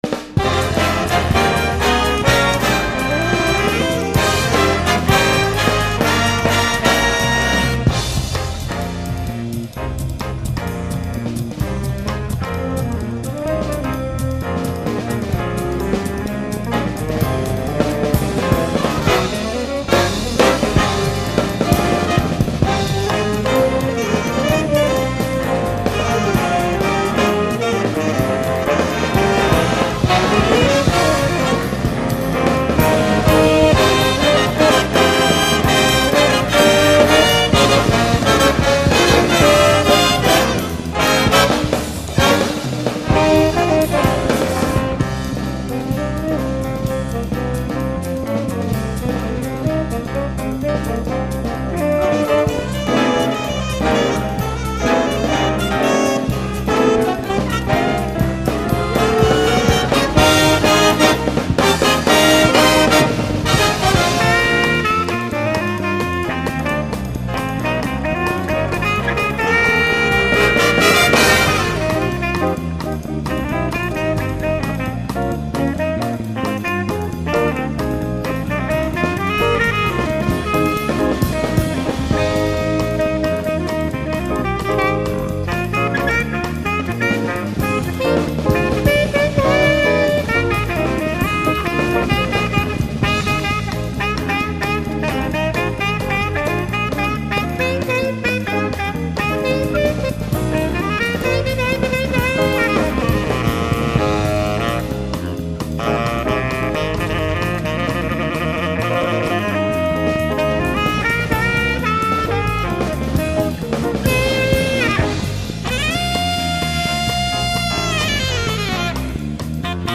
J A Z Z   B A N D S